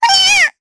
Estelle-Vox_Damage_jp_5.wav